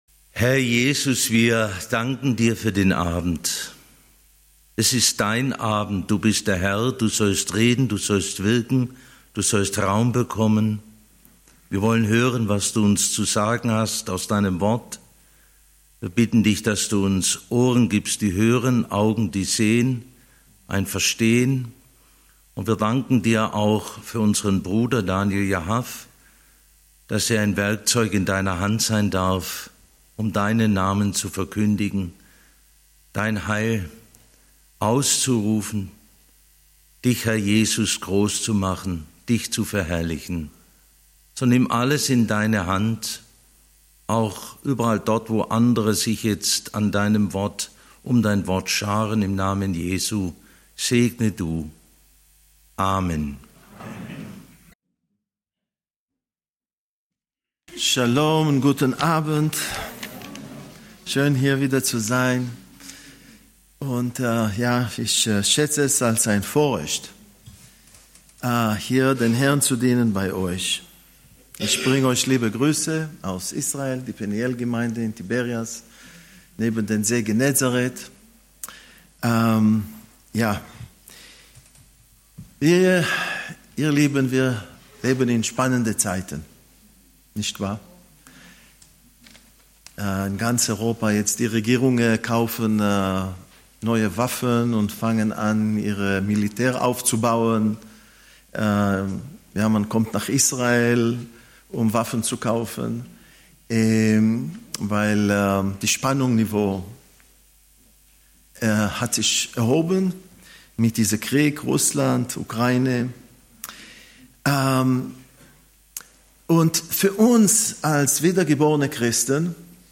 Die aktuelle Situation in Israel und der Konflikt mit Iran - Bibelstunde